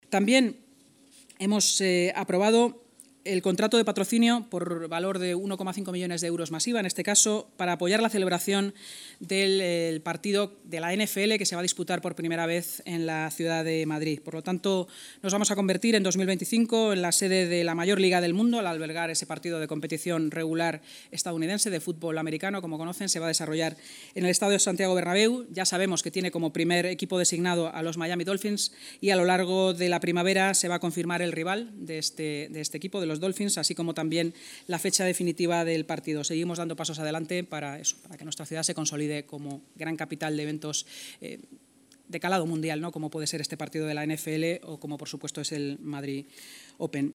Nueva ventana:La vicealcaldesa de Madrid y portavoz municipal, Inma Sanz